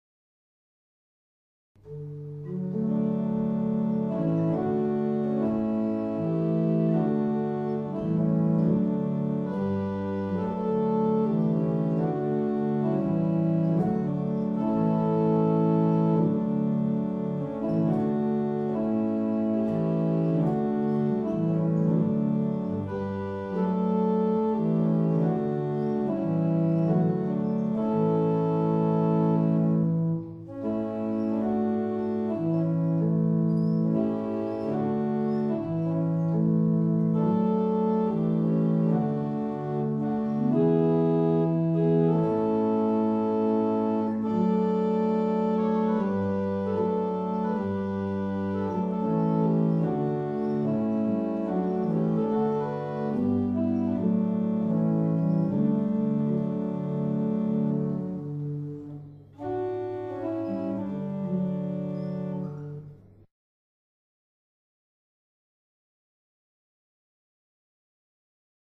St Michael, Fobbing (Essex)
Barrel Organs in English Parish Churches
Stop list: Open Diapason 8’, Stopped Diapason 8’, Dulciana 8’, Principal 4’.
Tunes recorded in 1996.
Improved barrel organ.